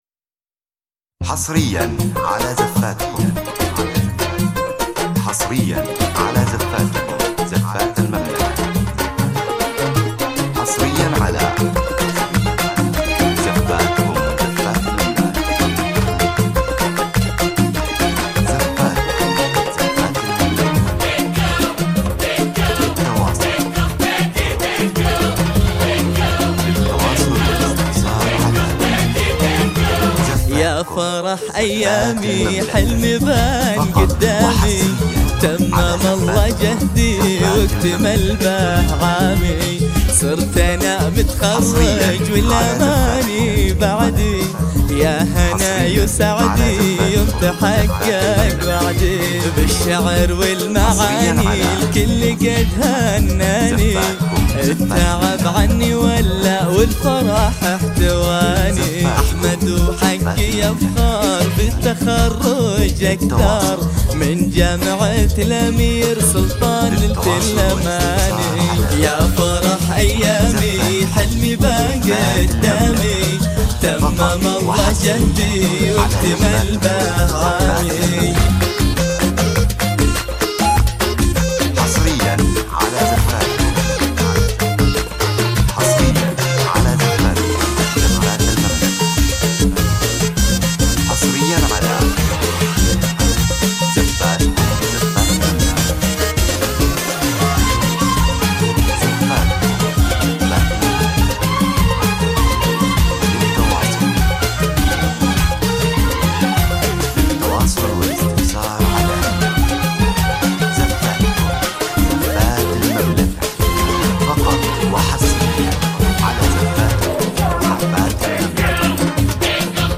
زفات تخرج
زفة تخرج موسيقية حصرية
تعكس مشاعر الفخر والإنجاز في لحظات التخرج